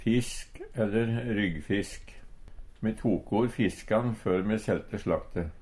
fisk/ryggfisk - Numedalsmål (en-US)